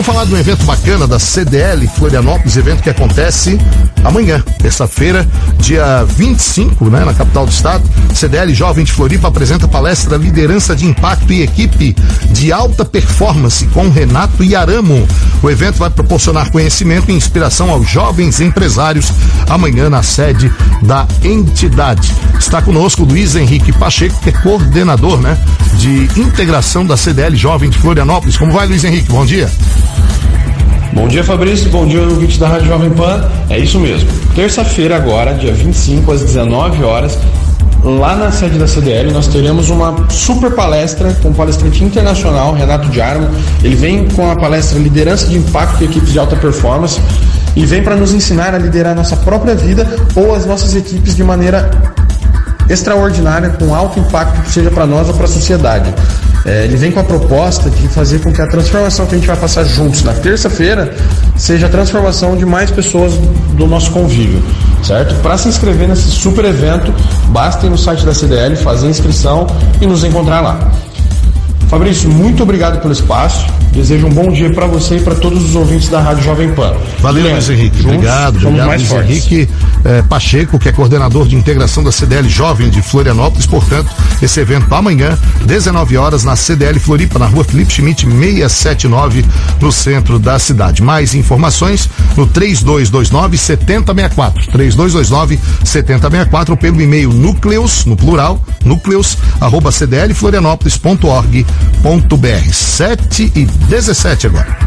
CDL de Florianópolis - Rádio